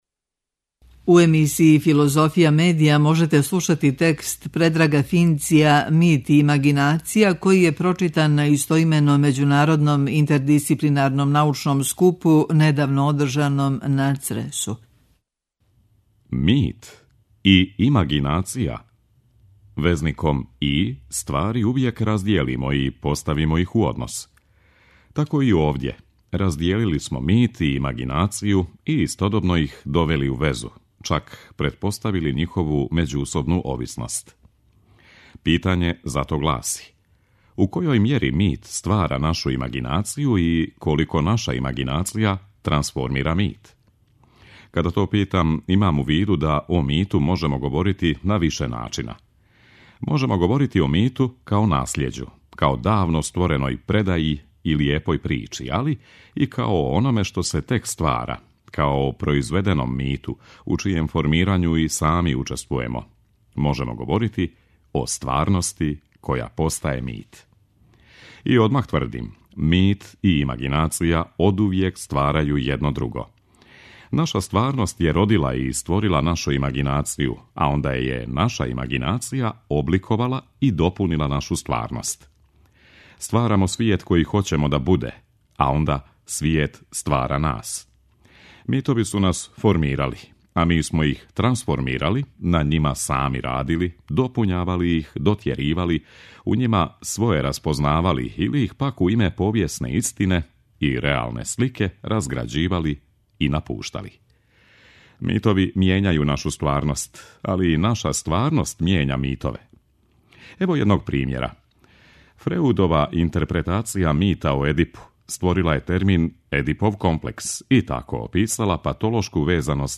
преузми : 15.25 MB Трибине и Научни скупови Autor: Редакција Преносимо излагања са научних конференција и трибина.